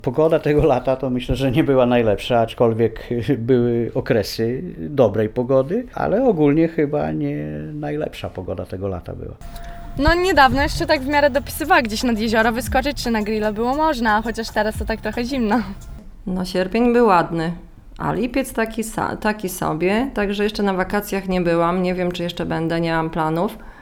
Zapytaliśmy mieszkańców Suwałk w jaki sposób spędzali swój wolny czas. Jak się okazuje większość z nas spędziła urlop na miejscu, pod przysłowiową „gruszą” lub w polskich miejscowościach turystycznych i kurortach.